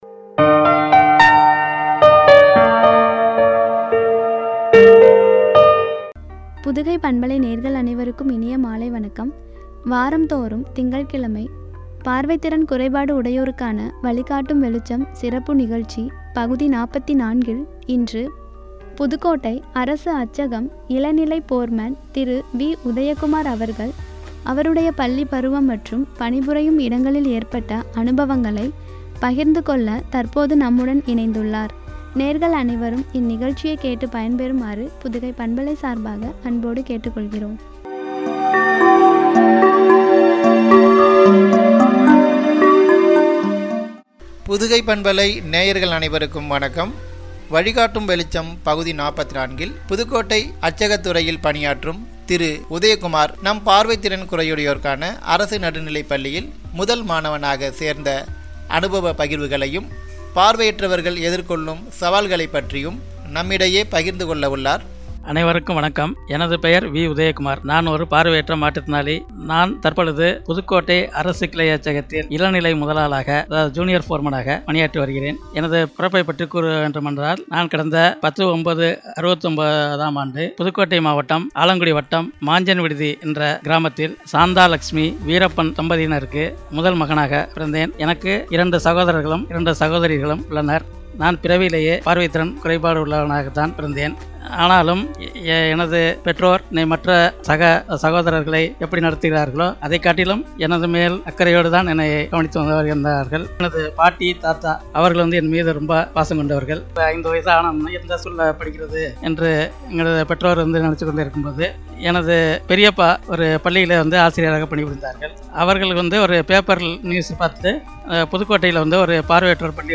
வழிகாட்டும் வெளிச்சம்- (பகுதி 44)” (அனுபவப் பகிர்வு)